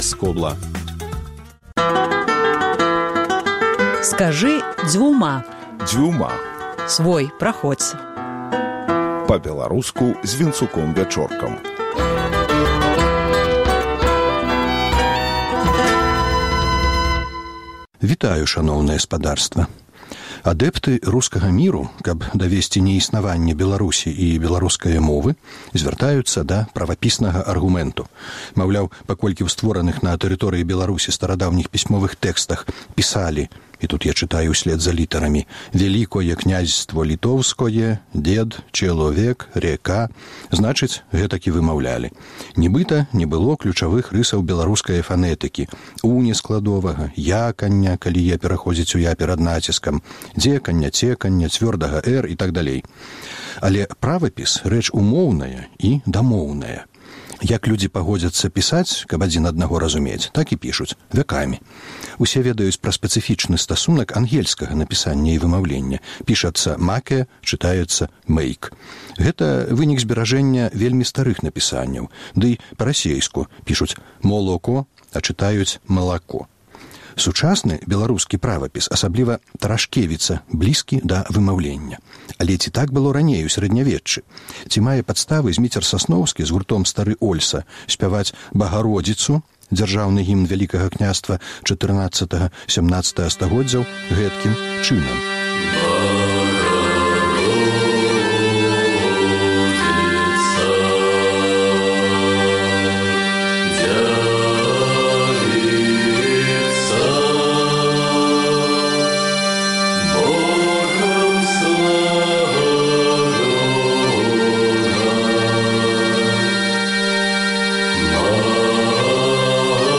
Наша мова за некалькі стагодзьдзяў адолела царкоўнаславянскі ўплыў. Чытаем старыя тэксты зь беларускім вымаўленьнем. 2. Адно зь першых рашэньняў Ураду БНР — пра дзяржаўнасьць беларускае мовы.